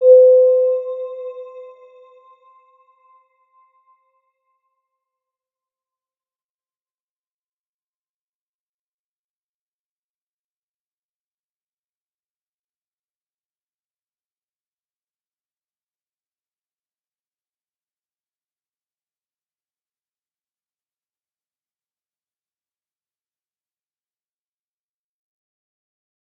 Round-Bell-C5-p.wav